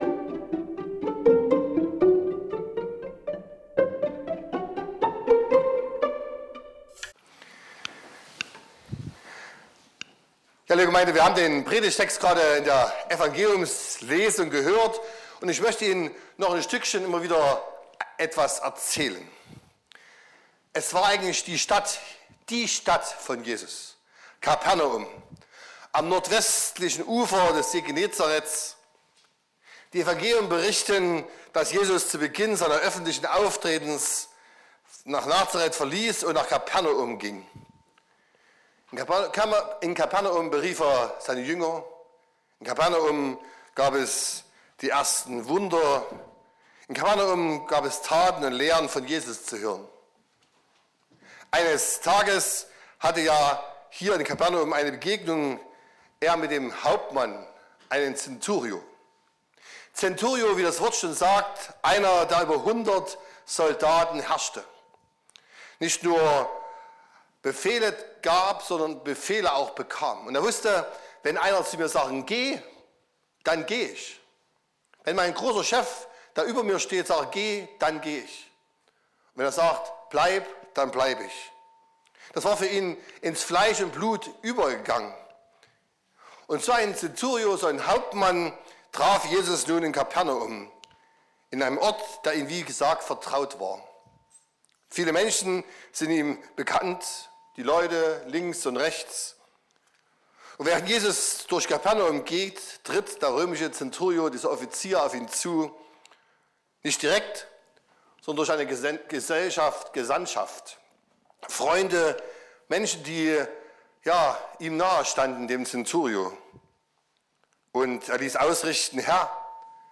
12.01.2025 – Gottesdienst
Predigt und Aufzeichnungen